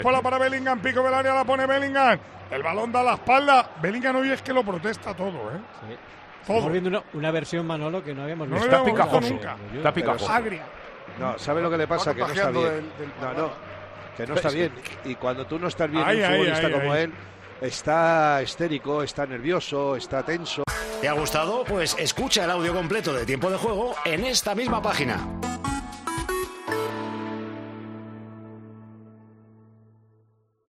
Esa postura de la nueva estrella blanca durante el choque que no pasó desapercibida para el equipo de Tiempo de Juego durante la retransmisión del encuentro, con los comentarios de Tomás Guasch y Poli Rincón, y lo achacaron a esos problemas físicos.